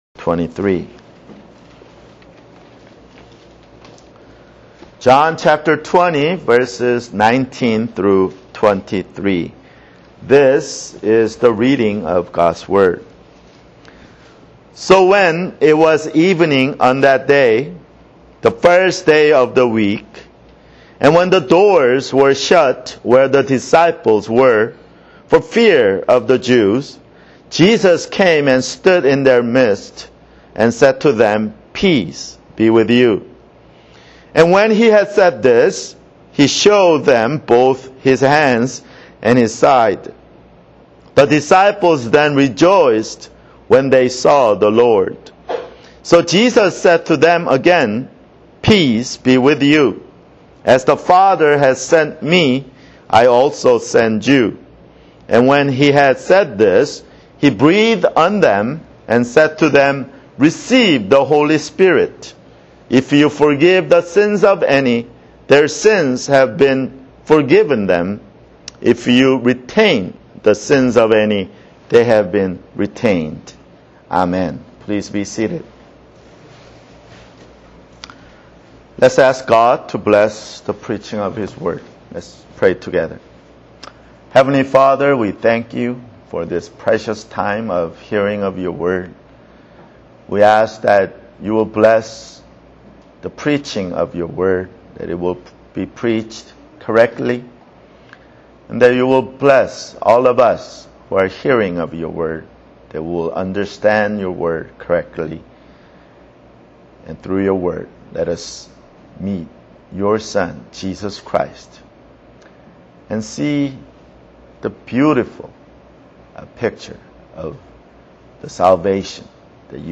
[Sermon] John 20:19-23 (4)